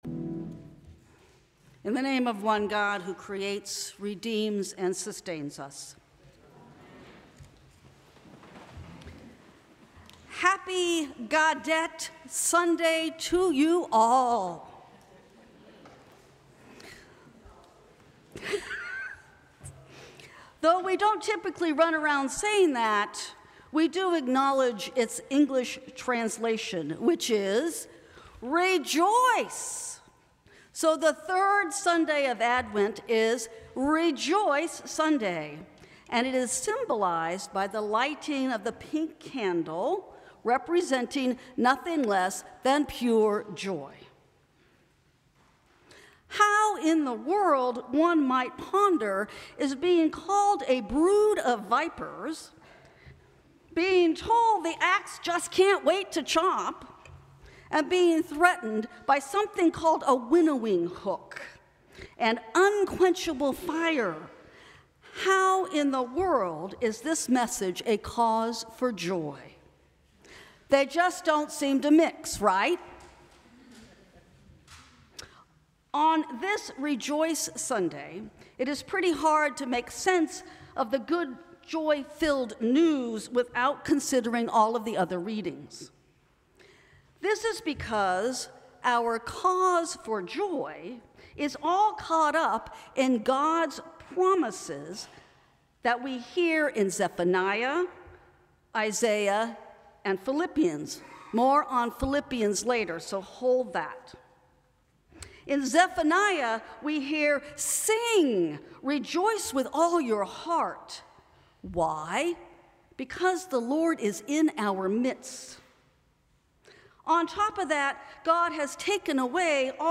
Sermons from St. Cross Episcopal Church Third Sunday of Advent Dec 22 2024 | 00:11:27 1x 00:00 / 00:11:27 Subscribe Share Apple Podcasts Spotify Overcast RSS Feed Share Link Embed